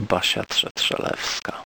Barbara Stanisława "Basia" Trzetrzelewska[4] (Polish: [ˈbaɕa tʂɛtʂɛˈlɛfska]
Pl-Basia_Trzetrzelewska.ogg.mp3